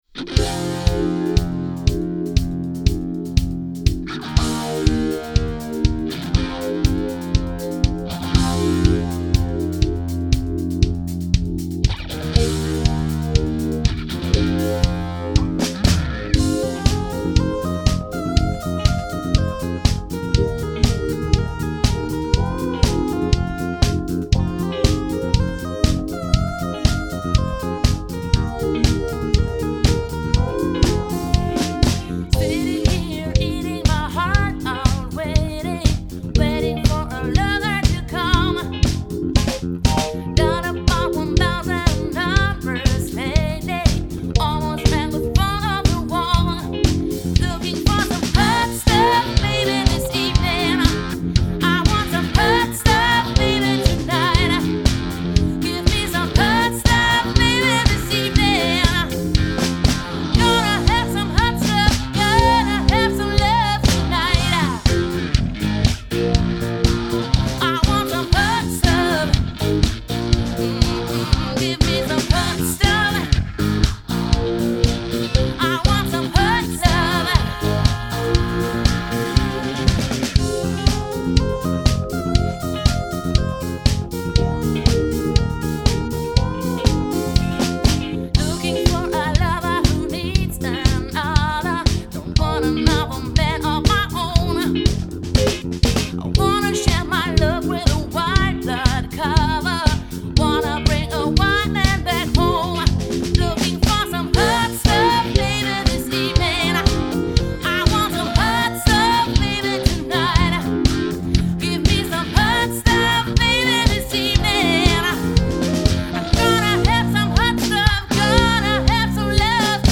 100 % Live Musik!